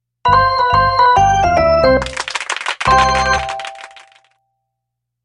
Звуки победы в игре